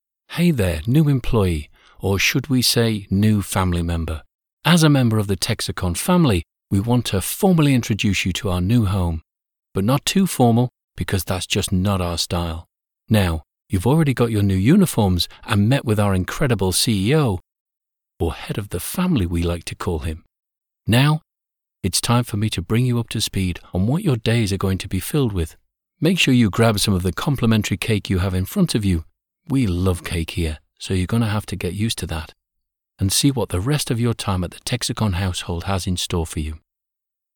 Male
My voice is mature and deep with an authoritative, conversational style. Other characteristics of my voice are believable, articulate and sincere.
Texicon Explainer Video